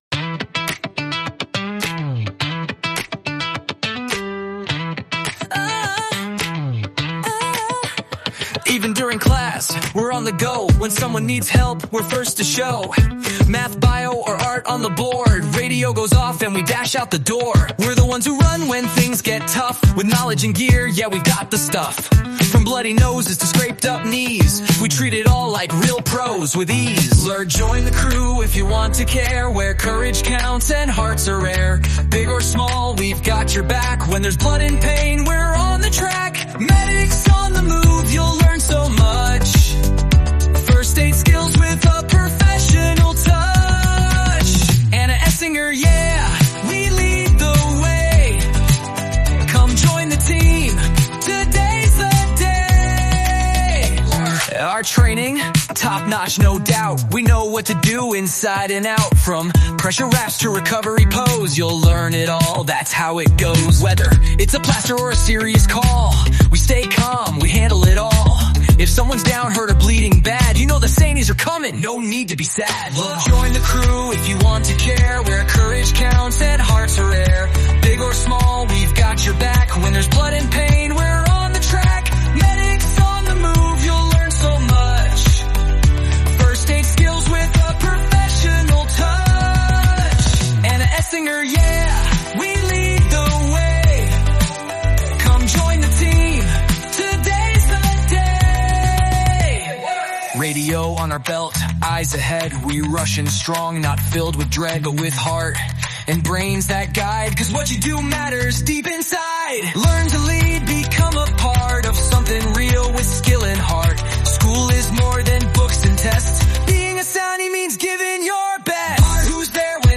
Mit diesem Lied im Ohr bleibt man bei der Reanimation im Takt